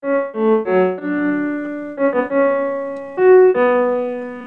Le son obtenu est alors bien celui de la séquence de la fugue, mais joué sur un autre instrument...
Les premières notes privées de l'attaque (GoldWave)